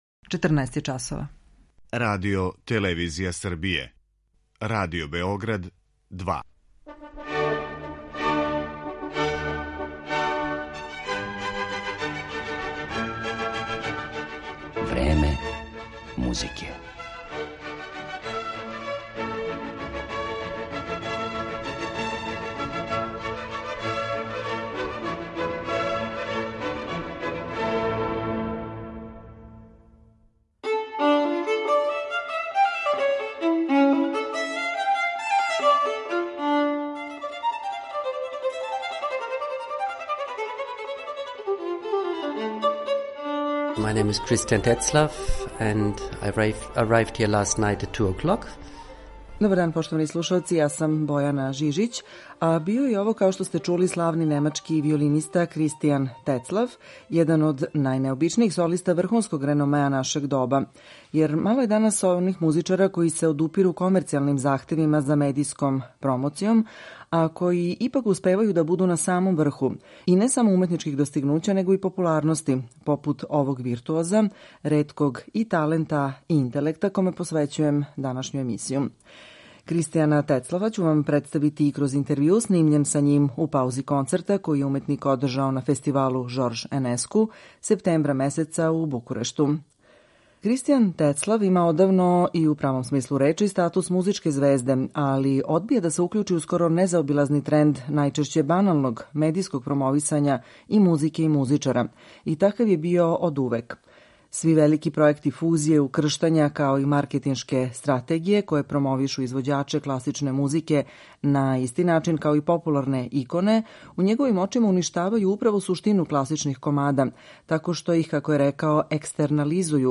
Један од таквих је изузетни немачки виолински виртуоз Кристијан Тецлаф, кога ћемо данас представити и кроз ексклузивни интервју снимљен са њим 2017. године у Букурешту.